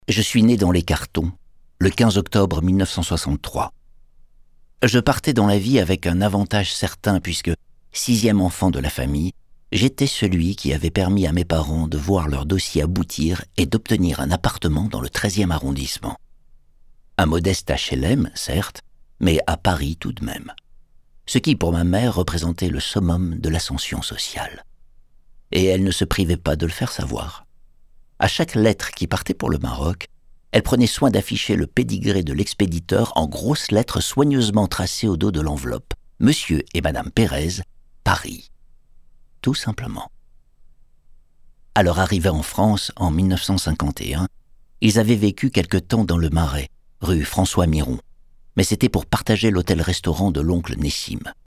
Livres Audio
« Ma mère, dieu et Sylvie Vartan » de Roland Perez Lu par Laurent Natrella de La Comédie-Française